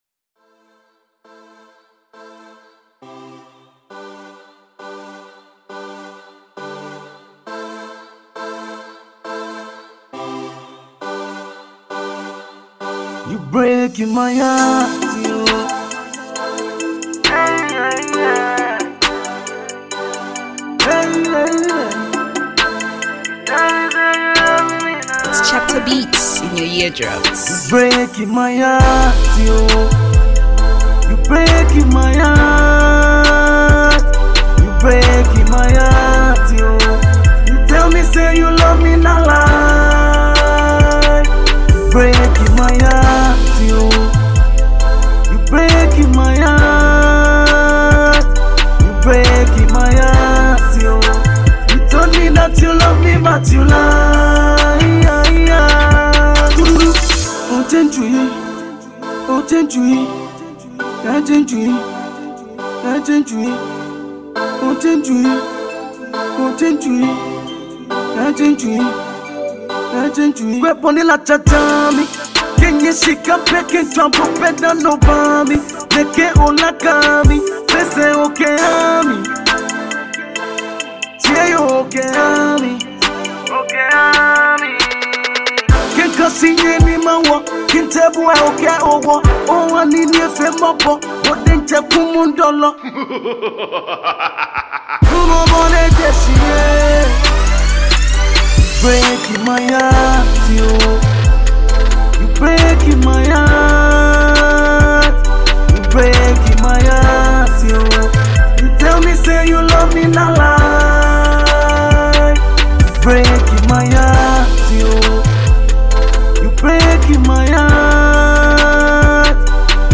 GHANA MUSIC
Hip Hop jam